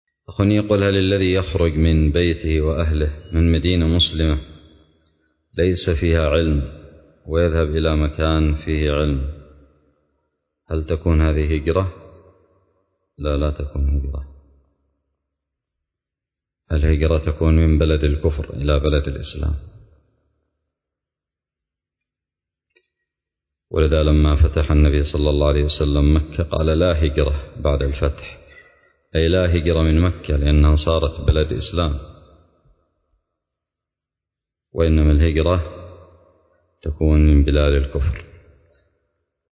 :العنوان فتاوى عامة :التصنيف 1445-1-5 :تاريخ النشر 21 :عدد الزيارات البحث المؤلفات المقالات الفوائد الصوتيات الفتاوى الدروس الرئيسية الذي يخرج من بلد مسلم ليس فيها علم ويذهب بلد فيه علم هل هذه هجرة؟ سؤال قدم لفضيلة الشيخ حفظه الله